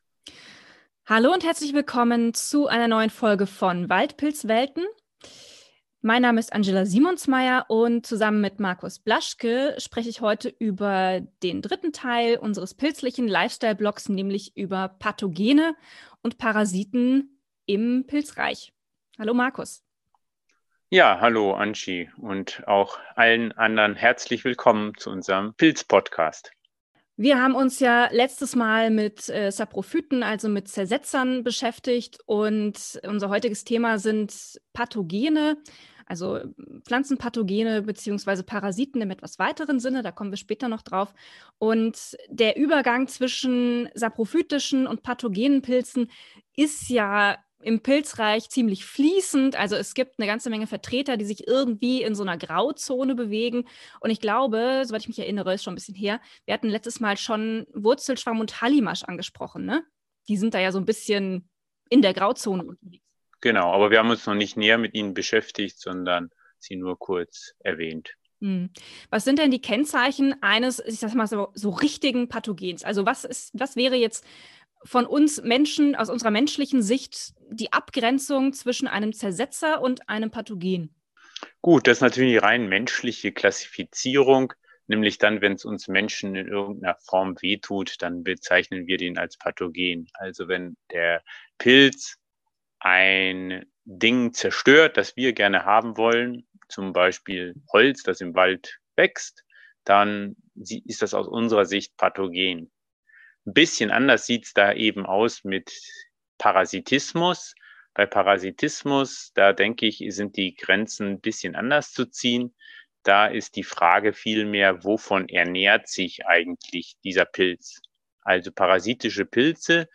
Im letzten Teil unseres Themenblocks zum pilzlichen Lifestyle geht es um Parasiten, bzw. um die Pilze, die wir als Pathogene bezeichnen. Zwei Phytopathologen und ihr Lieblingsthema - das kann was werden ;-) Veröffentlichungsdatum: 01.08.2021